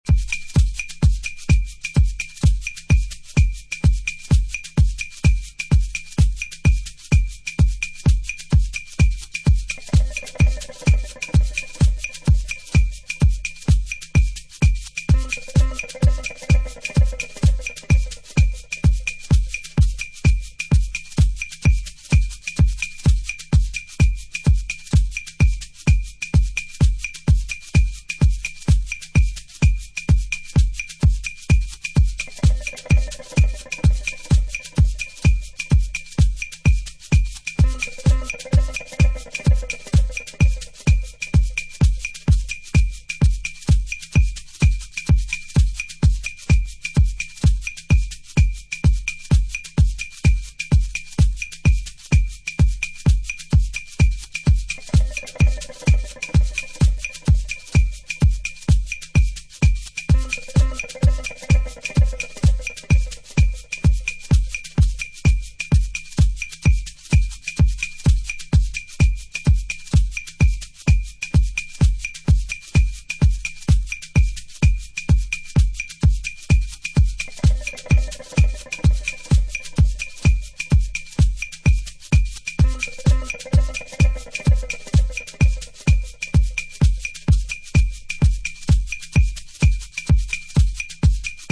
ダブやボーナス・ビートも収録！